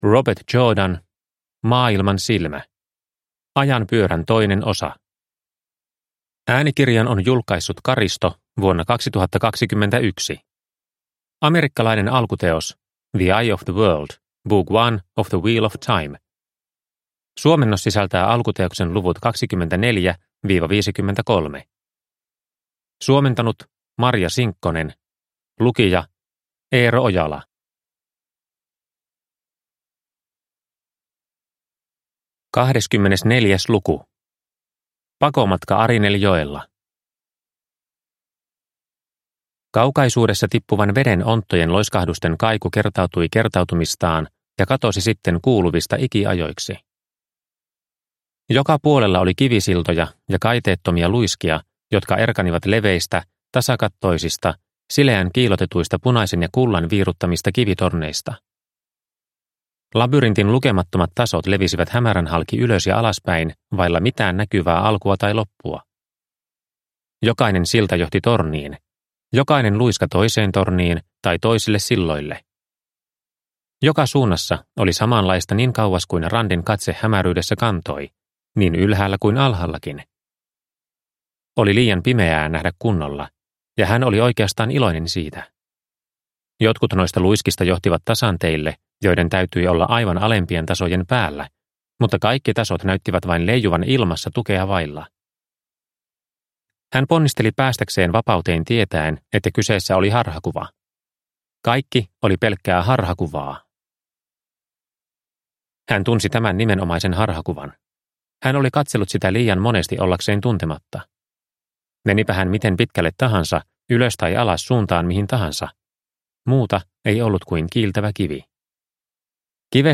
Maailmansilmä – Ljudbok – Laddas ner